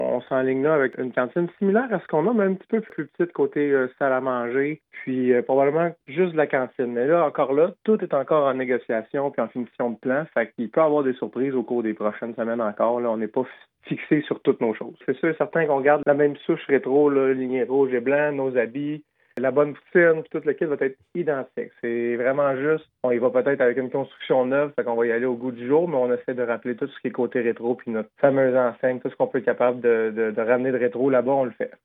L’annonce en a été faite ce matin en exclusivité, sur les ondes de M105 Le Matin